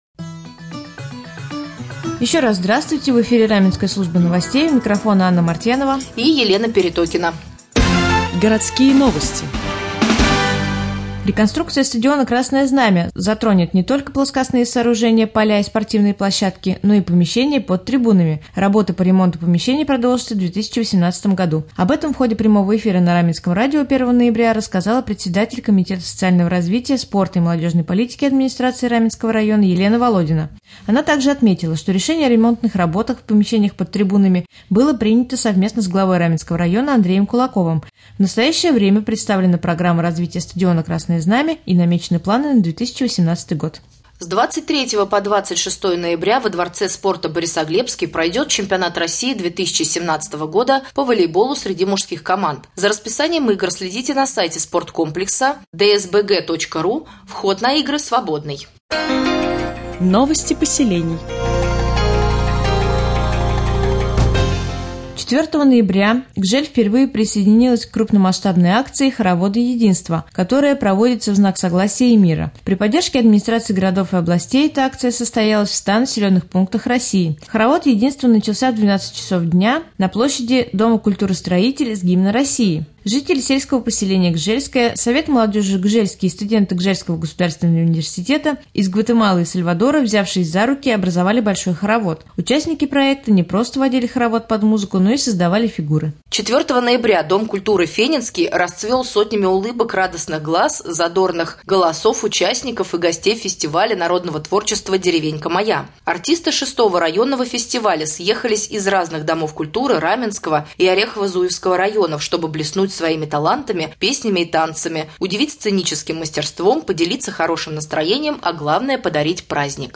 Сегодня в новостном выпуске на Раменском радио Вы узнаете, какие работы по реконструкции стадиона Красное Знамя продолжат в 2018 году, когда в Раменском пройдет Чемпионат России по волейболу среди мужских команд, а также последние областные новости и новости соседних районов.